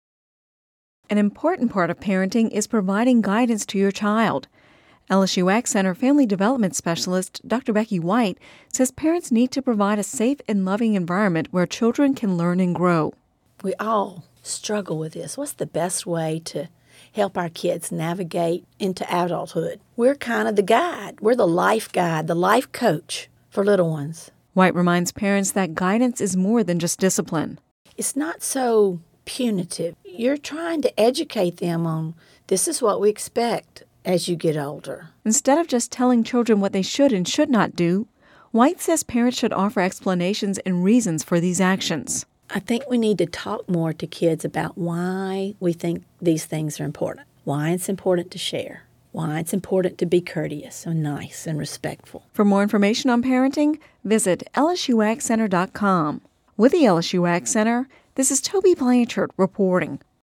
(Radio News 05/31/10) An important part of parenting is providing guidance to your child.